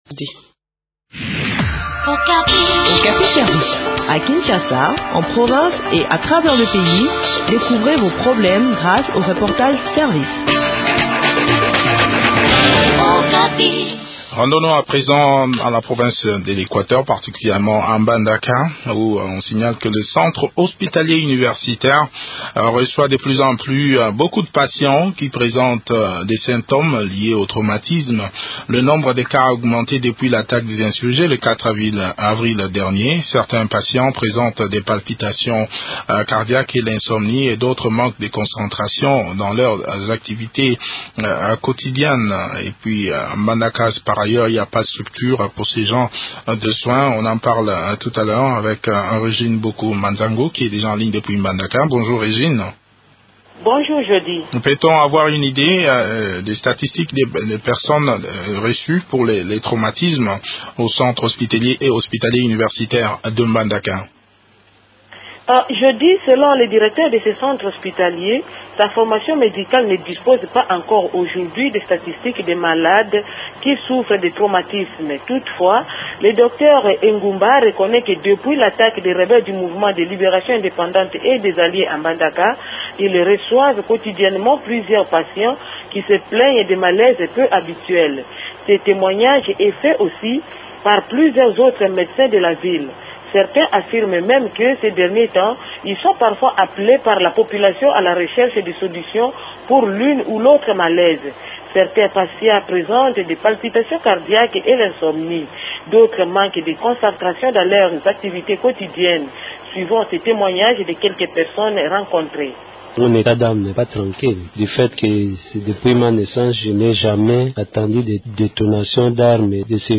Le point de la situation dans cet entretien